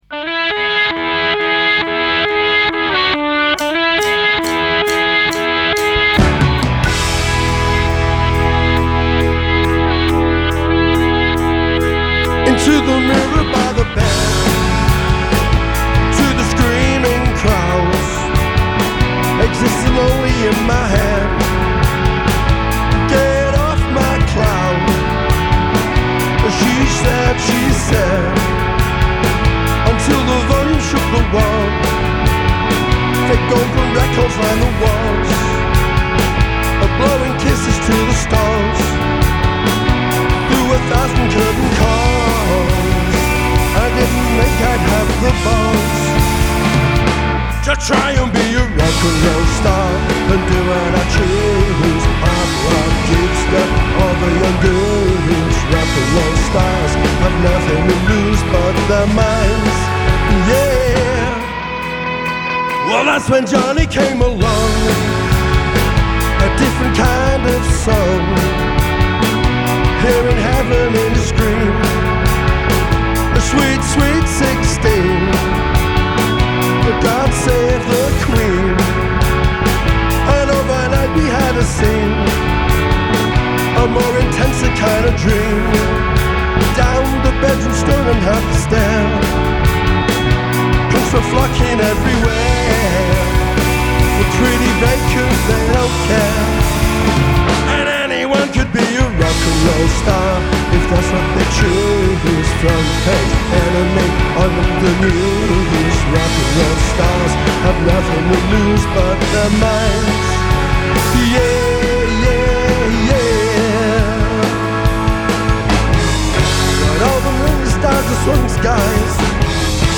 recorded live earlier today